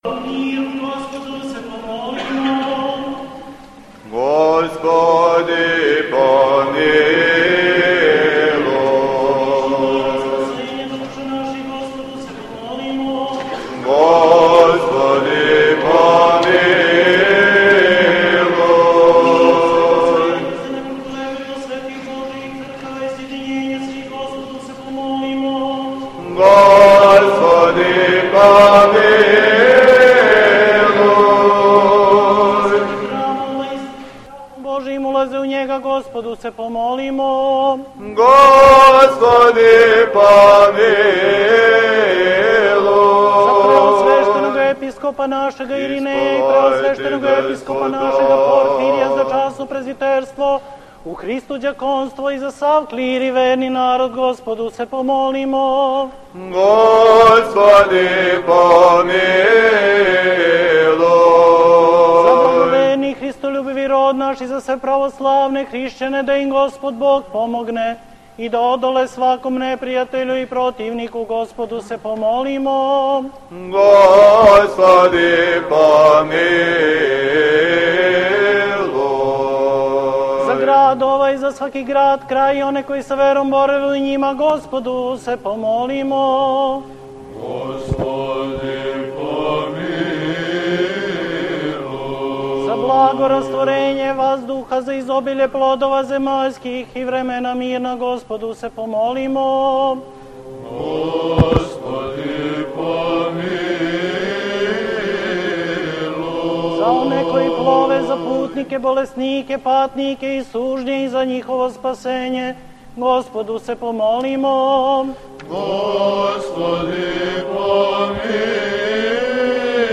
На празник Васкрсења Христовог, 24. априла 2011. године, у Саборној цркви у Новом Саду служено је свечано Пасхално вечерње.
Свештено сабрање, на којем је према древном поретку наше Цркве читан одељак из Еванђеља по Јовану, предводио је предстојатељ Цркве Божје у Новом Саду и Бачкој, Епископ Иринеј.
На овом Вечерњем Љубави које је благоукрашено појањем хора Светоархангелског манастира у Ковиљу и хора студената Богословског факултета у Београду, зачало о сусрету васкрслог Господа са апостолима и Томином исповедању вере прочитано је на двадесет језикâ: црквенословенском, српском, грчком, јеврејском, латинском, арапском, руском, немачком, енглеском, француском, италијанском, португалском, шведском, финском, славомакедонском, бугарском, словачком, мађарском, ромском и црквенословенском (српскословенском).